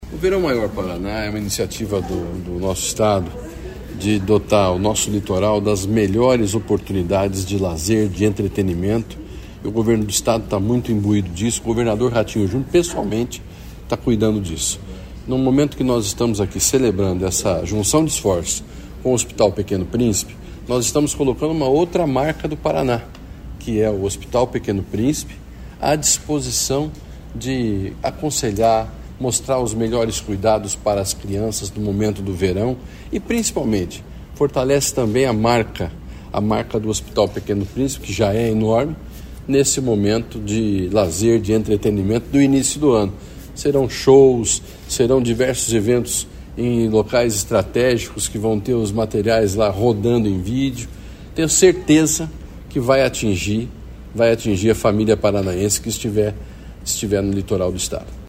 Sonora do secretário Estadual da Saúde, Beto Preto, sobre a parceria do Governo do Paraná com o Hospital Pequeno Príncipe para o Verão Maior